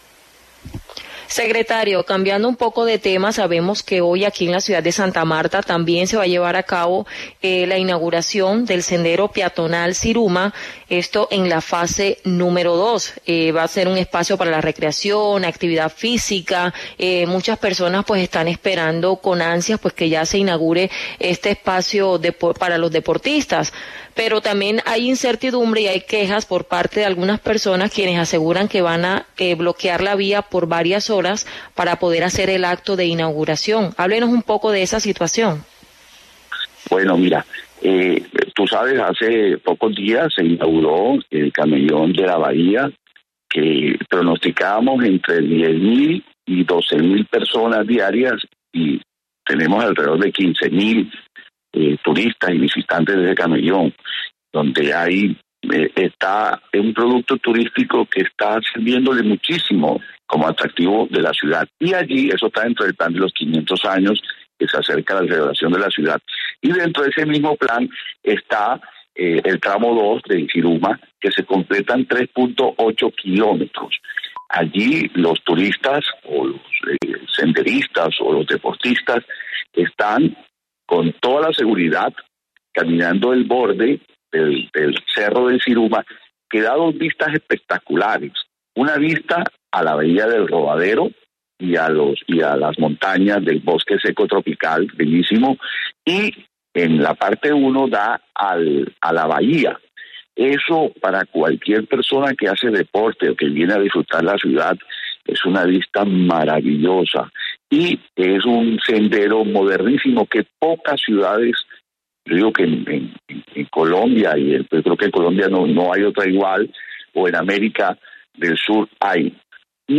Iván Calderón, secretario de Desarrollo Económico del Distrito de Santa Marta habla en La W sobre la inauguración del Sendero Peatonal Ziruma-Rodadero Fase II